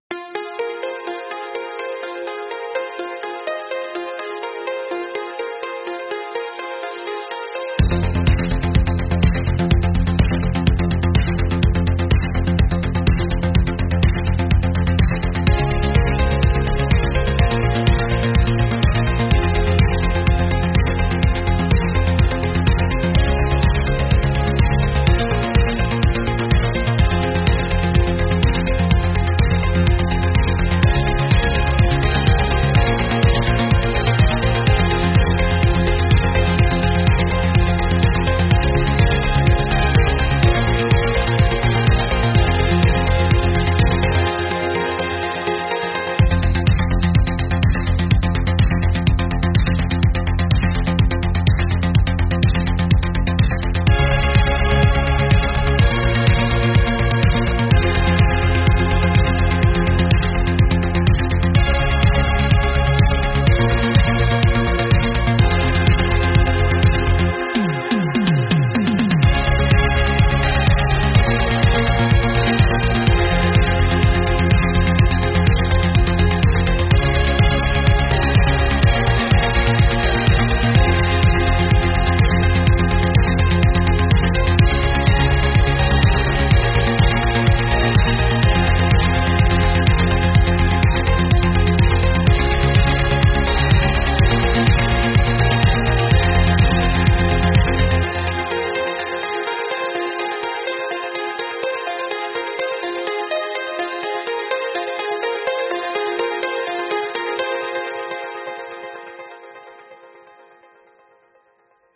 Instrumental - Real Liberty Media DOT xyz - Grimnir Radio